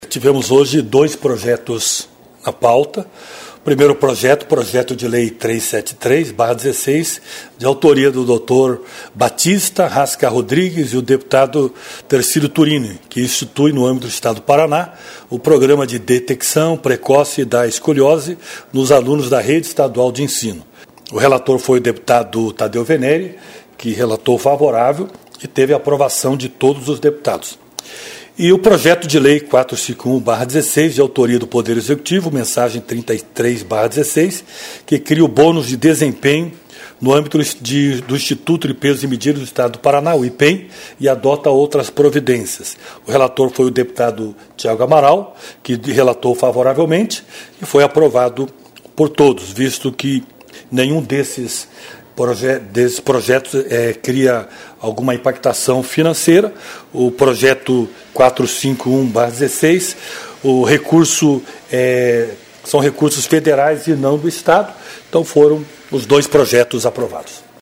O presidente da Comissão, deputado Gilson de Souza (PSC) fala dos dois projetos aprovados na reunião desta quarta-feira (19).